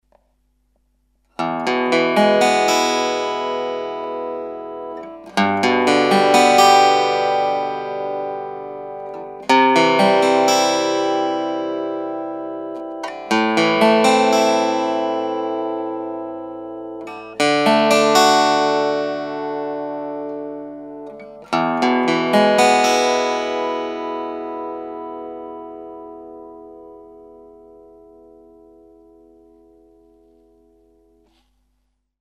・ギター：　'79 K.Yairi YW-500R
・プリアンプ：　L.R.Baggs Mixpro
・フラットピック使用
まず、15mmの方の音です。エフェクターは一切かけていません。
◆15mmピエゾの音（MP3:約504KB）